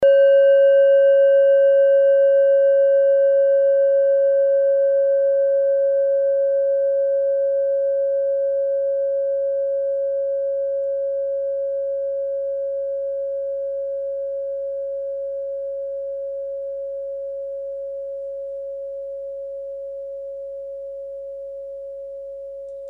Klangschalen-Typ: Bengalen
Klangschale Nr.5
Gewicht = 360g
Durchmesser = 11,2cm
(Aufgenommen mit dem Filzklöppel/Gummischlegel)
klangschale-set-1-5.mp3